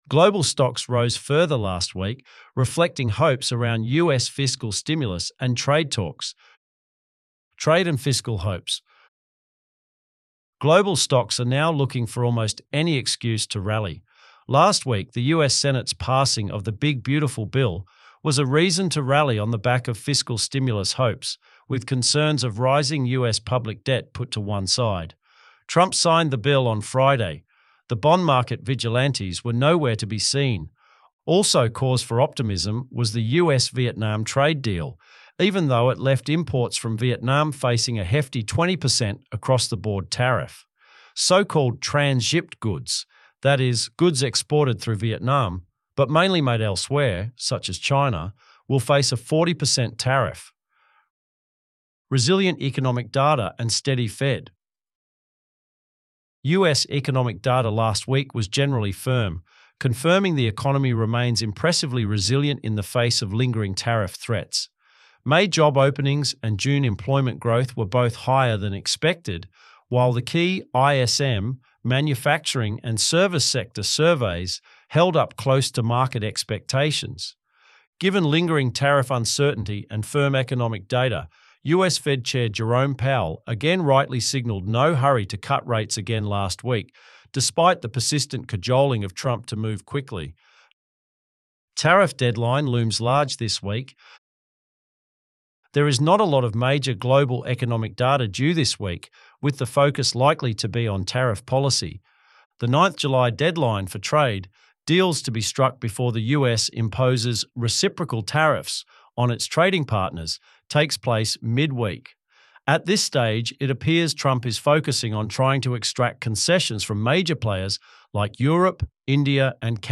ElevenLabs_Bass_Bites_-_7_July_2025.mp3